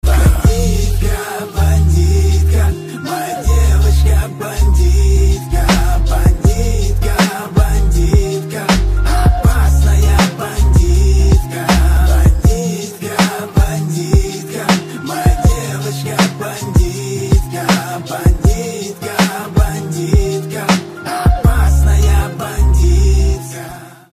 • Качество: 320, Stereo
мужской вокал
русский рэп
спокойные
клавишные